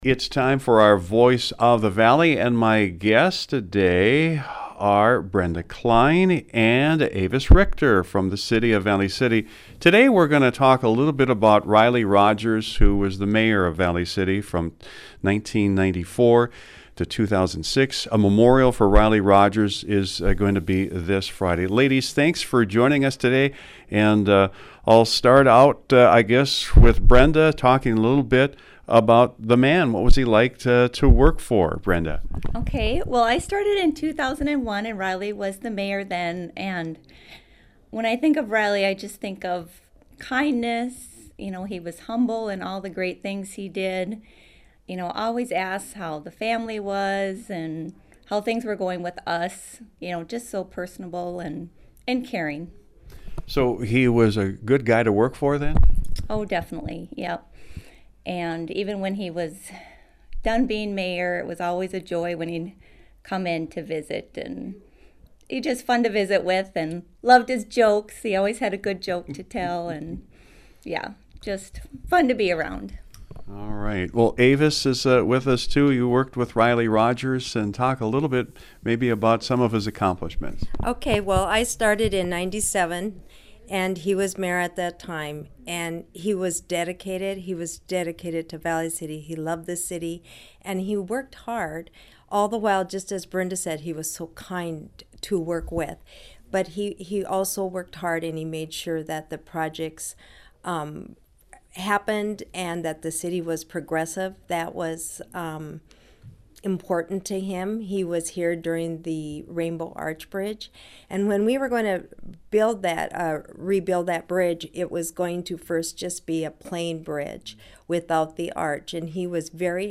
The following is a Voice of the Valley interview